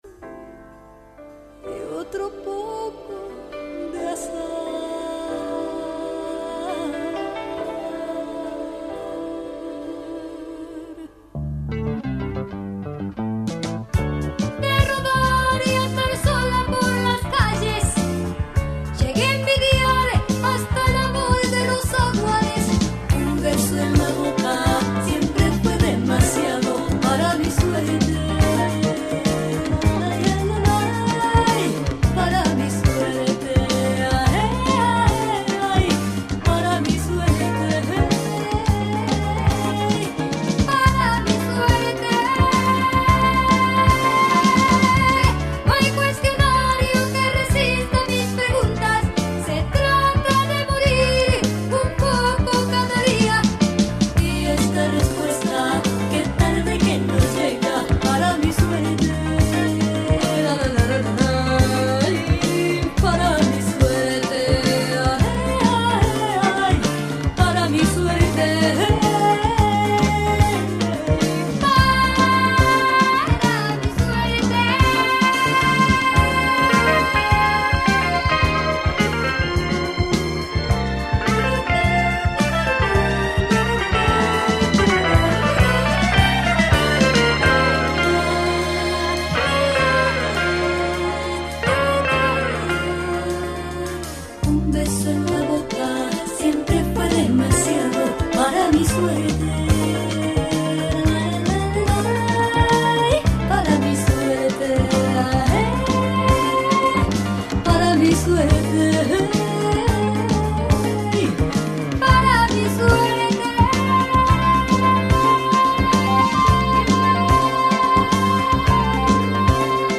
pretty good latin groove tunes with a pinch of Brazil